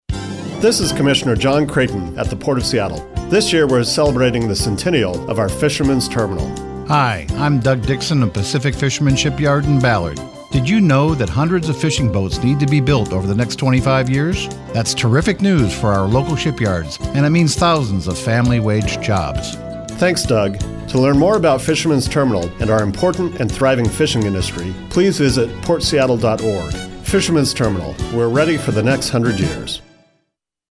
Whistle of the Virginia V